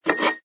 sfx_pick_up_bottle06.wav